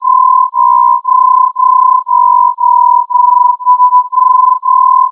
The THROB family of modes are MFSK in nature, but are unusual in that (like DTMF) they use two tones at a time.
Another unusual feature (which led to the name), THROB also includes raised cosine AM modulation of each symbol.
For THROBX, 11 tones are used, spaced 7.8125 or 15.625 Hz.
(ThrobX has 53 characters, 11 tones, and never sends single tones).
Throb2X.wav